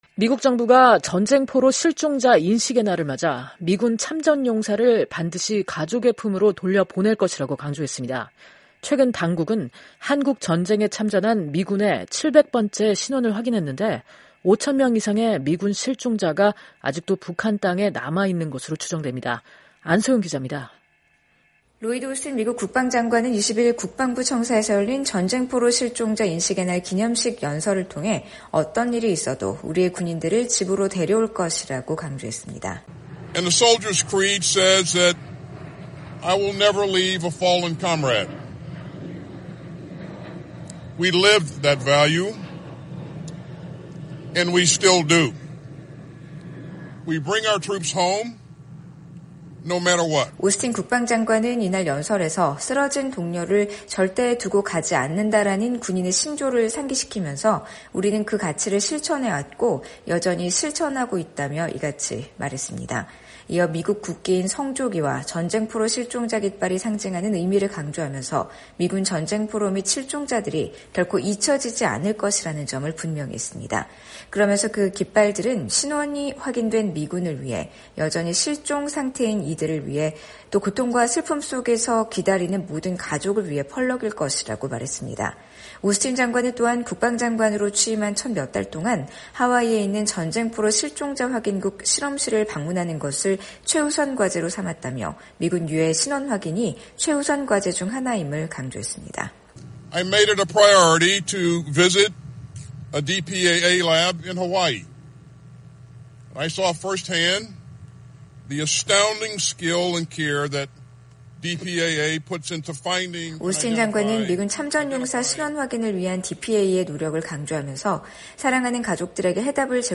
로이드 오스틴 미 국방부 장관이 20일 국방부 청사에서 열린 ‘전쟁포로 실종자 인식의 날’ 기념식에서 연설을 하고 있다.